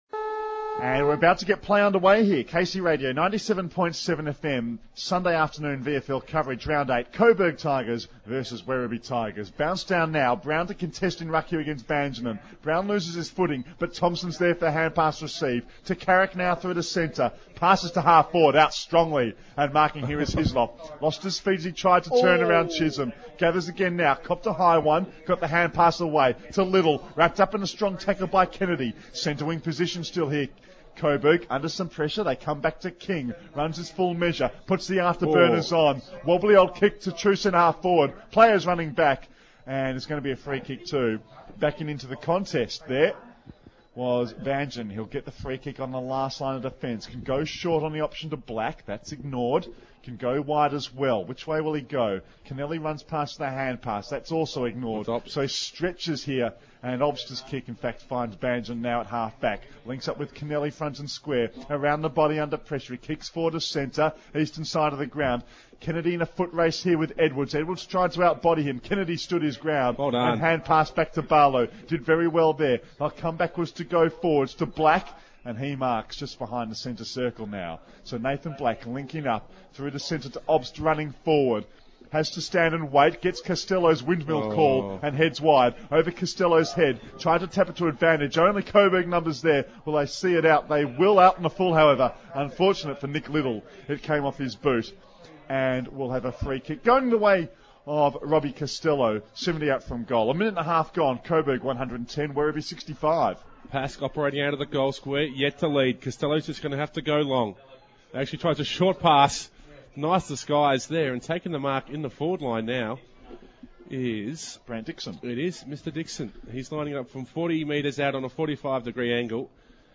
Casey FM's coverage of the Round Eight clash, Coburg Tigers v Werribee Tigers, at Chirnside Park, Werribee Commentators
Final quarter commentary